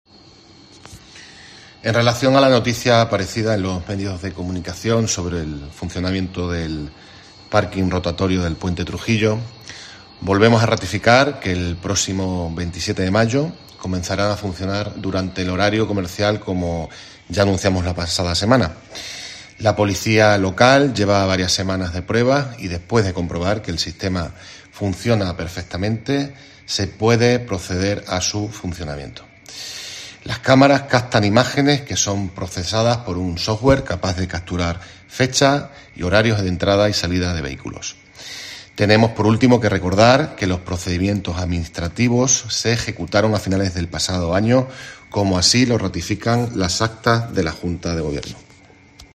David Dóniga, concejal de Interior Plasencia sobre las cámaras del parking puente Trujillo